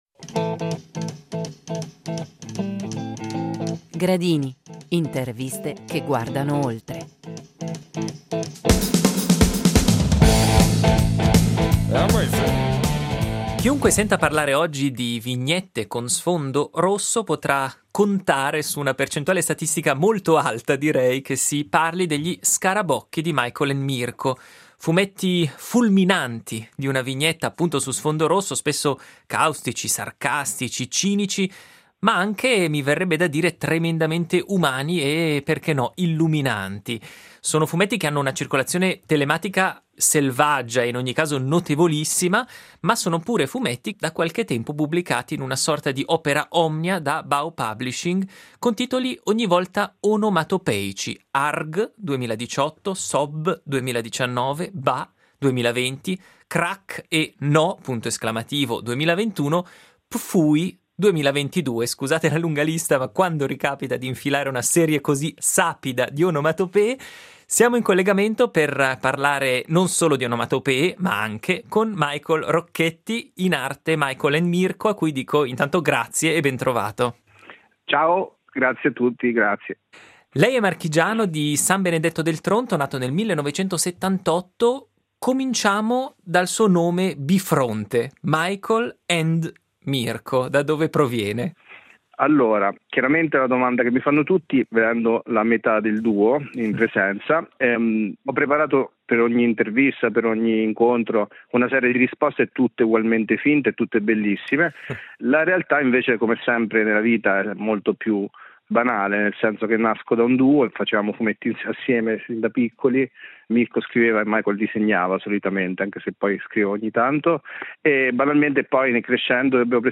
Gradini – Interviste che vanno oltre